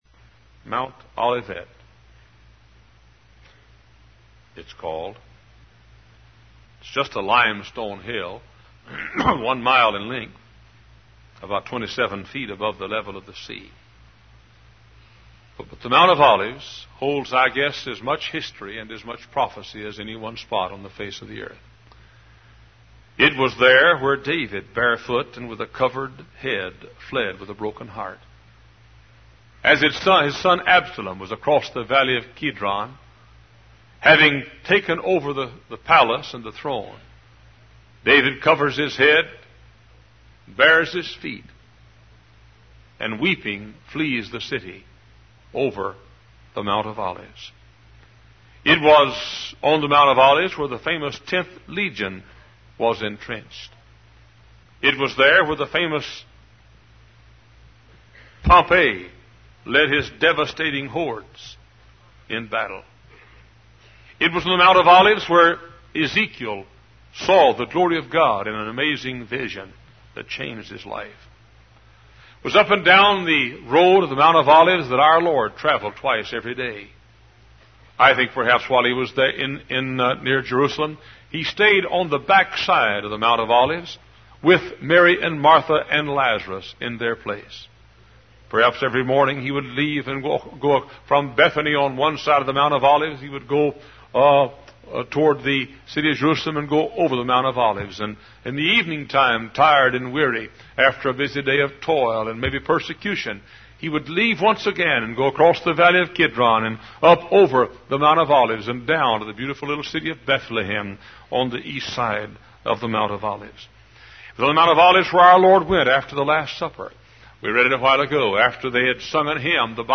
Professing themselves to be wise, they became fools. Wise Desire Ministries helps convey various Christian videos and audio sermons.